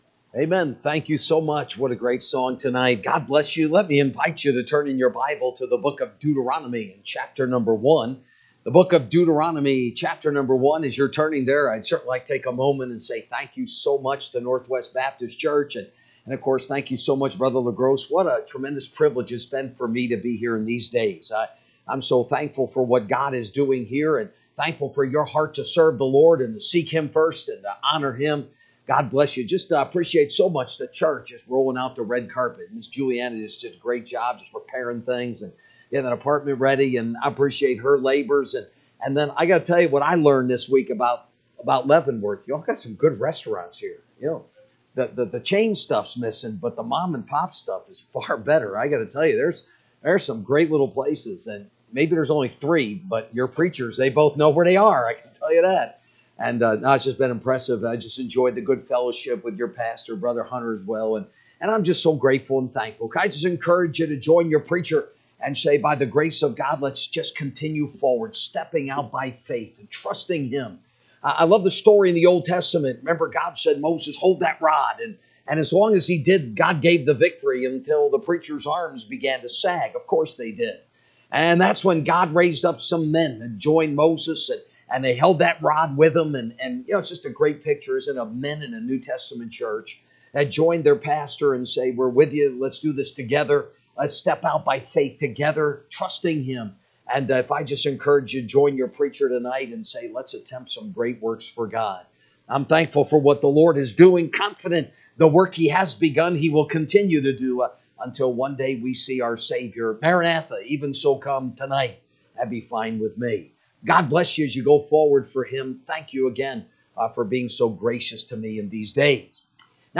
March 19, 2025 Wed Revival Service